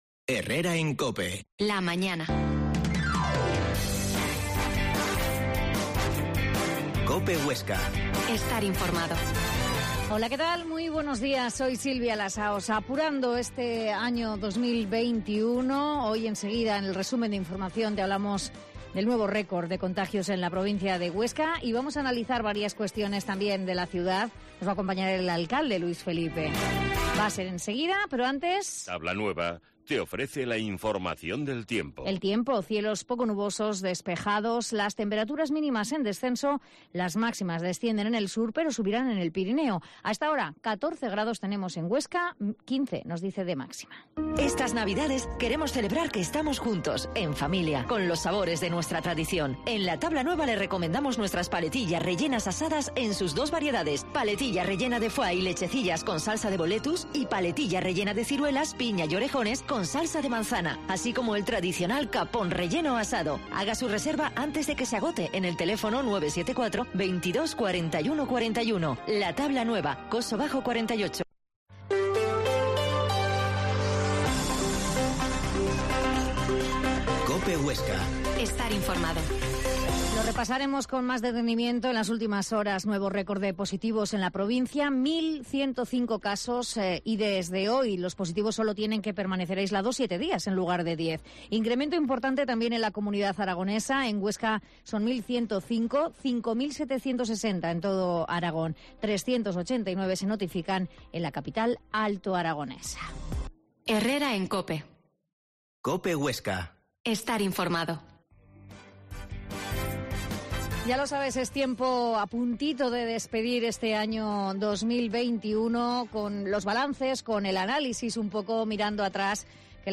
Herrera en COPE Huesca 12.50h Entrevista al alcalde de Huesca, Luis Felipe